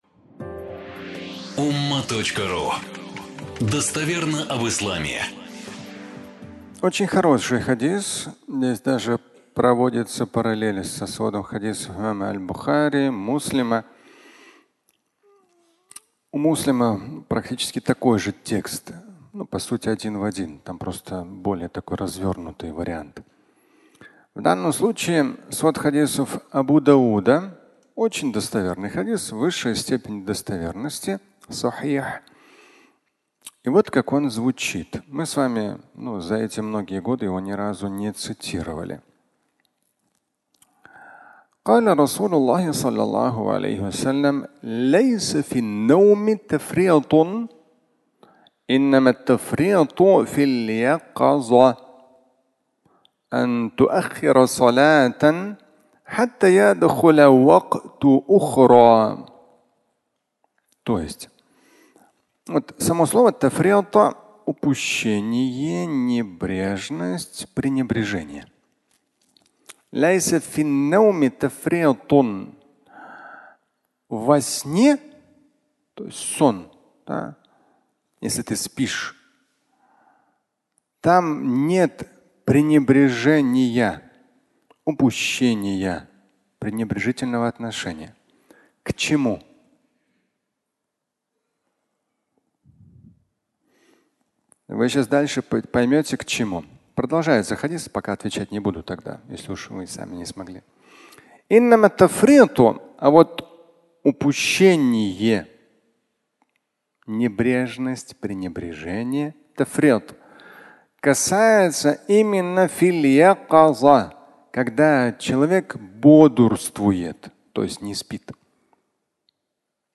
Не проснулся на утренний намаз (аудиолекция)